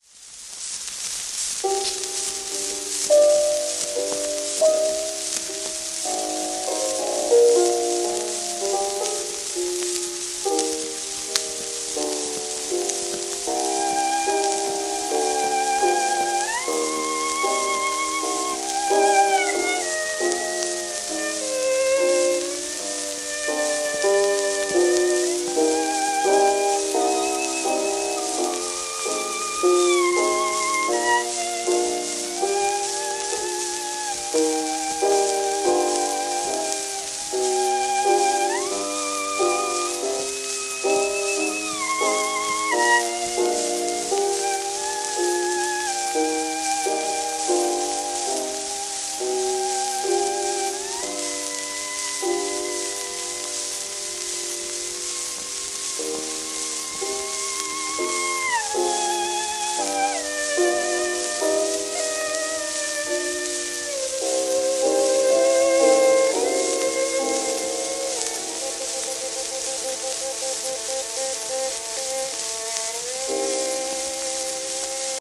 10インチ片面盤
1914年頃？の録音。
旧 旧吹込みの略、電気録音以前の機械式録音盤（ラッパ吹込み）